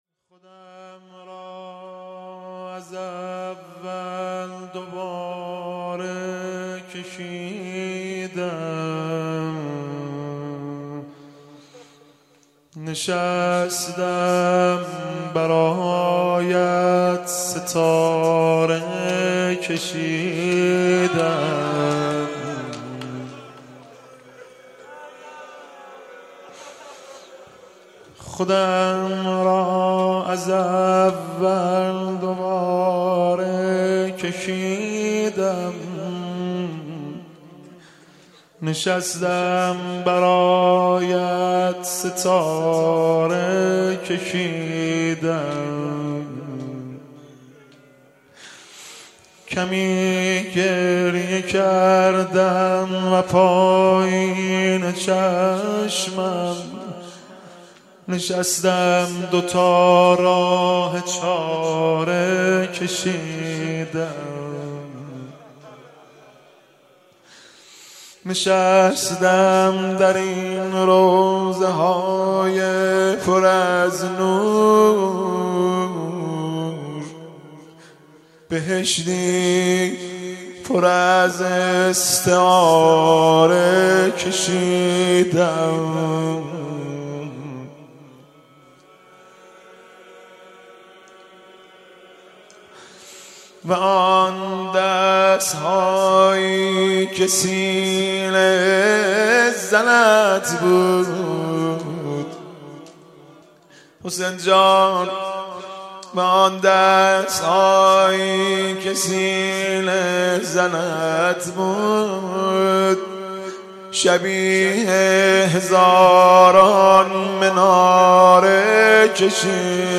صوت/ عزاداری شب سوم محرم با نوای مهدی رسولی
مراسم عزاداری دهه اول محرم الحرام